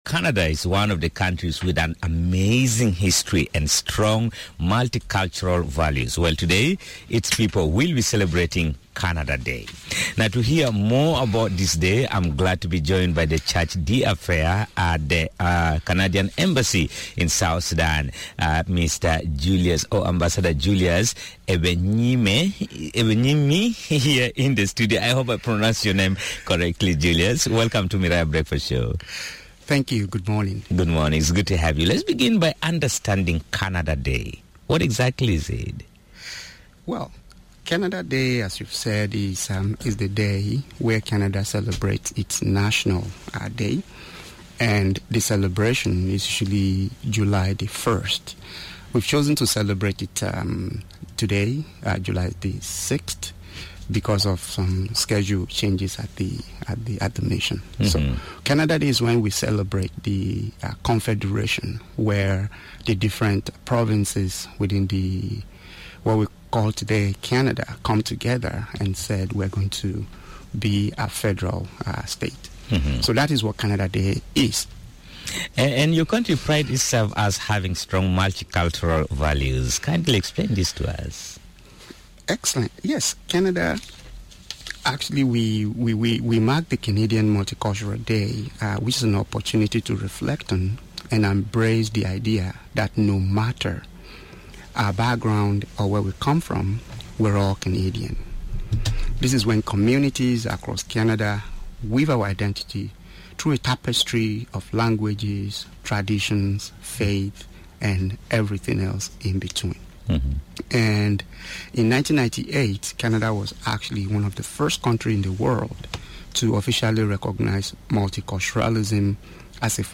Guest: Charge d’affaires at the Canadian Embassy - Julius Egbeyemi